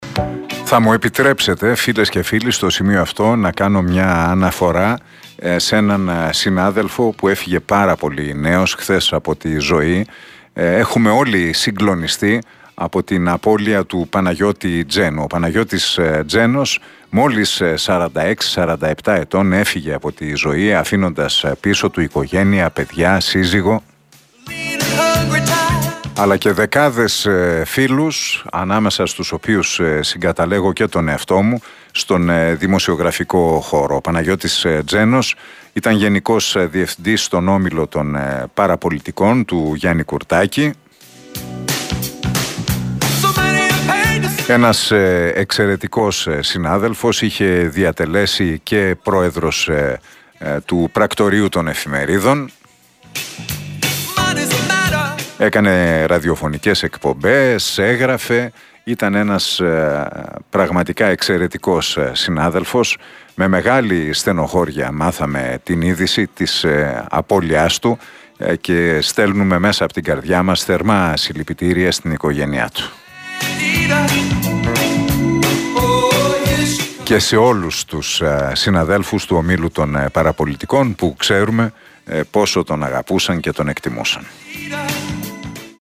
είπε ο Νίκος Χατζηνικολάου μέσα από την εκπομπή του στον Realfm 97,8.